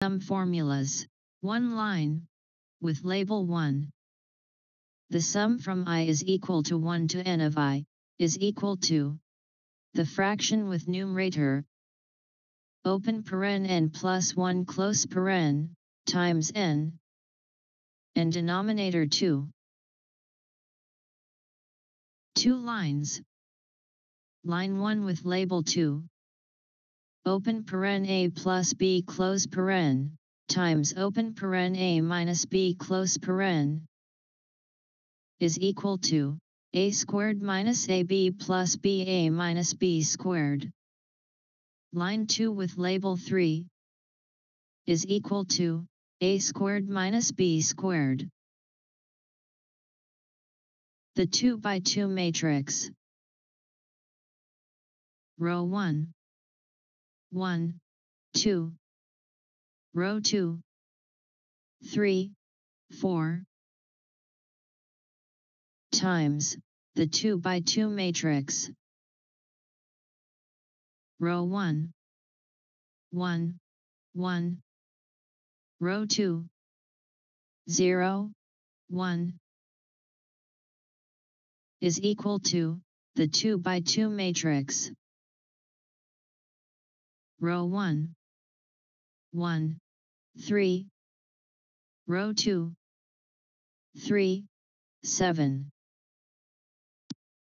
The audio renderings presented as as part of the talk are
2025-FMi-UFi-PDFA-tagged-math-clearspeak-audio.m4a